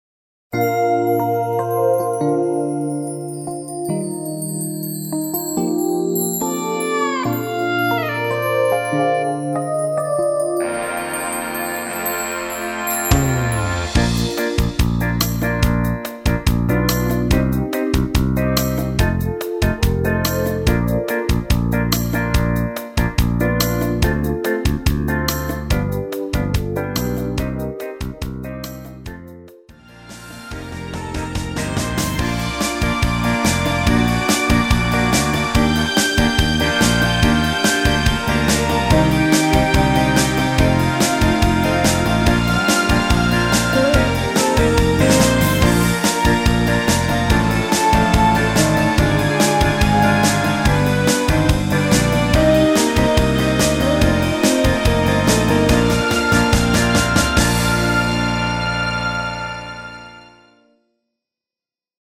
MR입니다.
엔딩이 페이드 아웃이라 라이브 하시기 좋게 엔딩을 만들었습니다.
키 Bb 가수